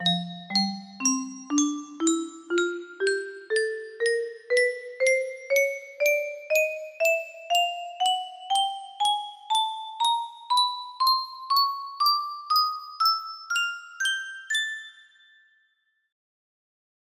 F Scale music box melody
Grand Illusions 30 (F scale)
30 Note Music Box F Scale Quarter notes